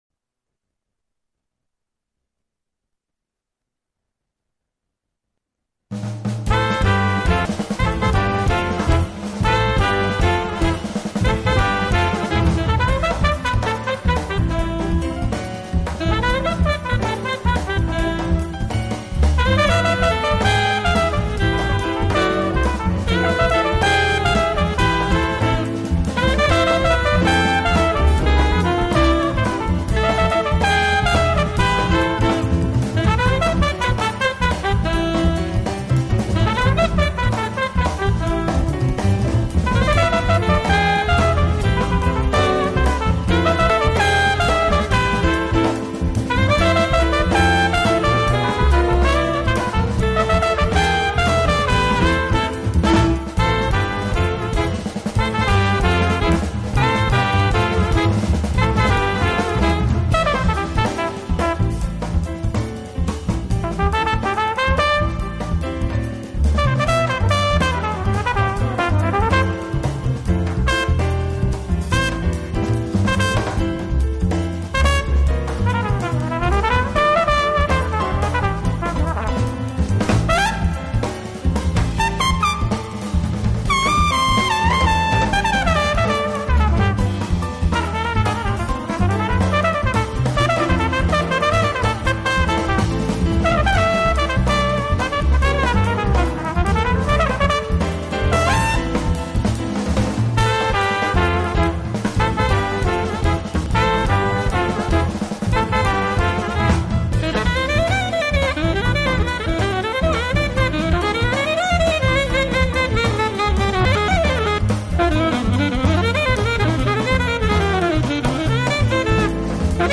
他擅长以略带忧郁音调的号声演绎浪漫 情歌。
专辑曲风：Jazz